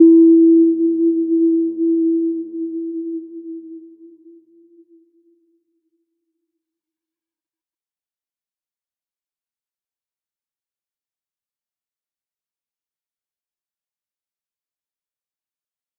Little-Pluck-E4-p.wav